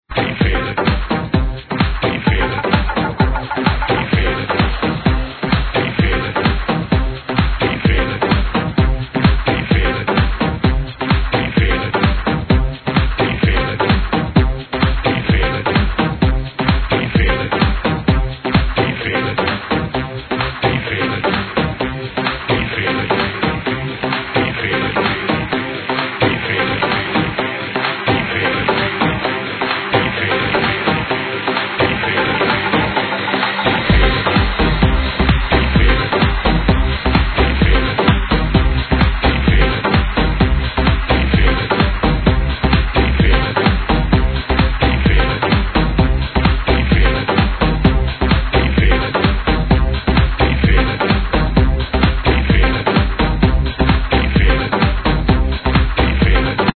House Tune